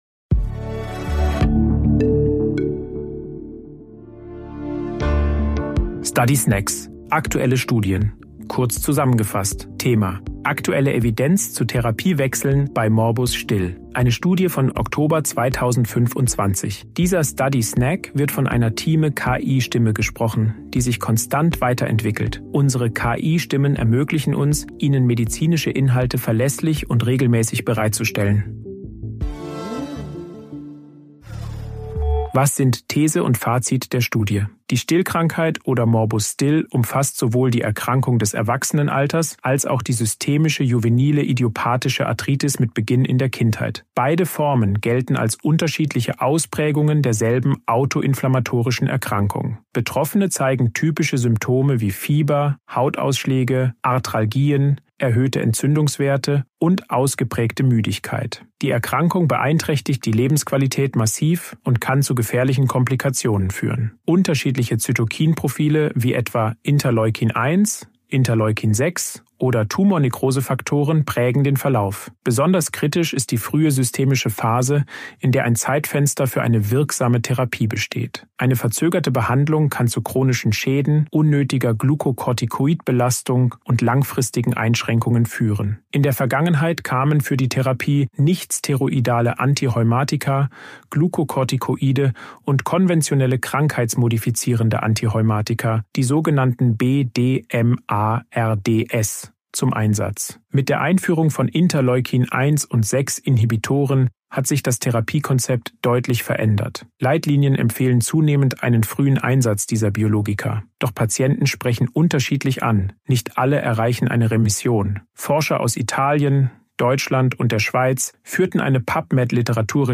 künstlicher Intelligenz (KI) oder maschineller